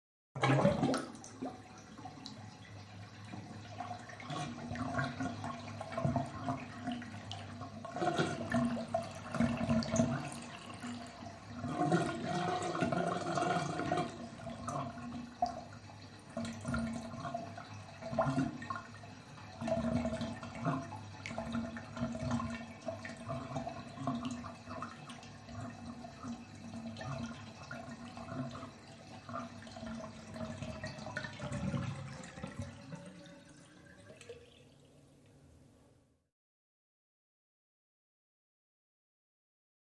Bathtub - Classic Draining, Good Rush Of Water